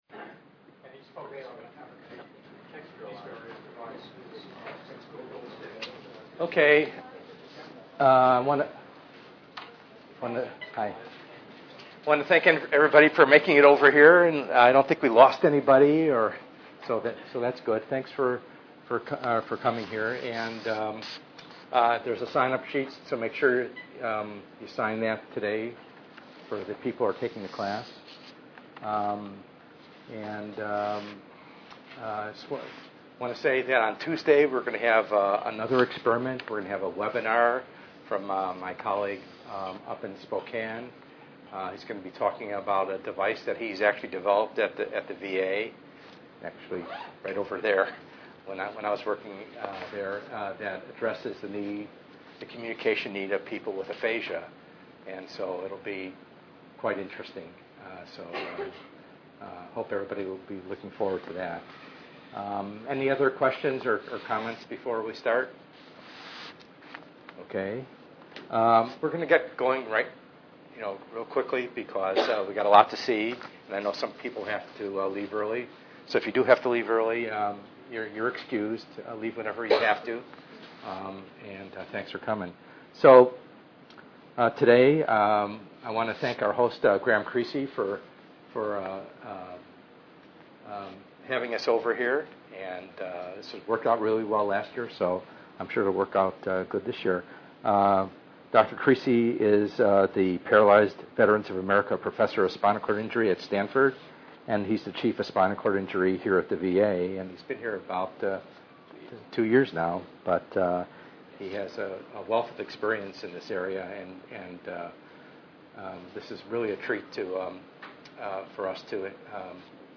ENGR110/210: Perspectives in Assistive Technology - Lecture 4b